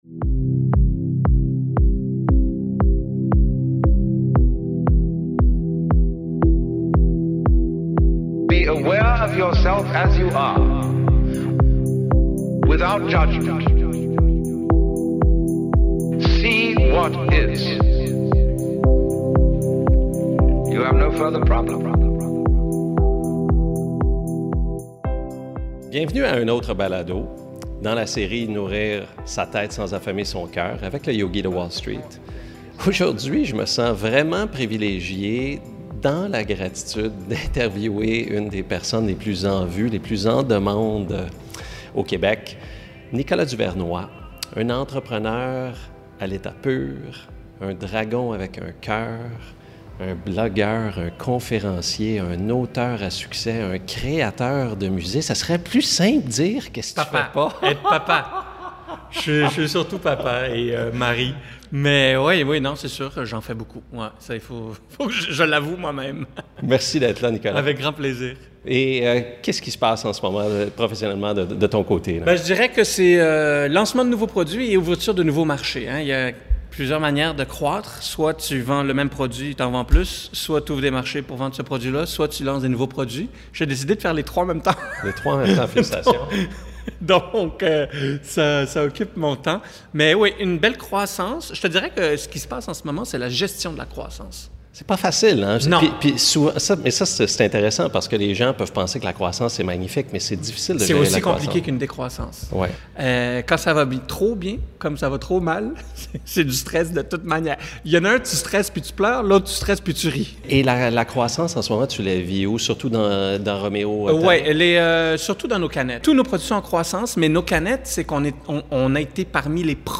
Rencontre avec Nicolas Duvernois